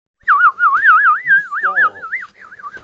Play Whistle can you stop, Download and Share now on SoundBoardGuy!
whistle-can-you-stop.mp3